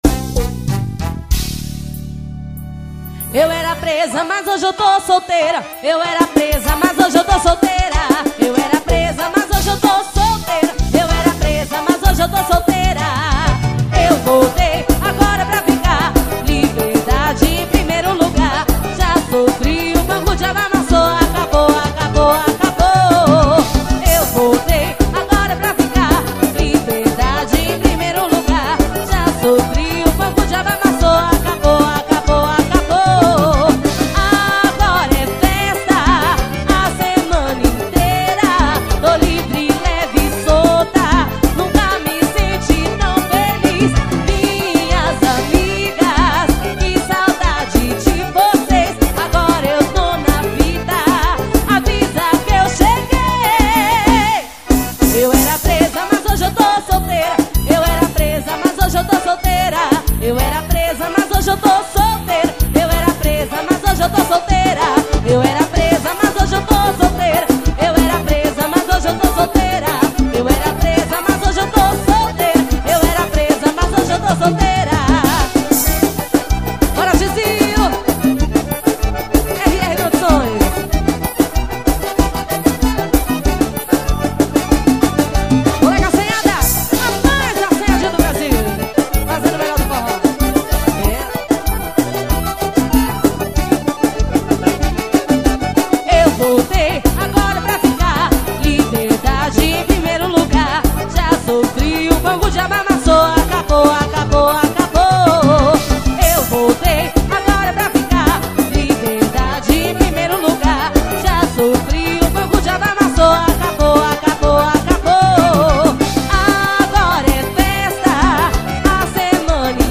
(AO VIVO)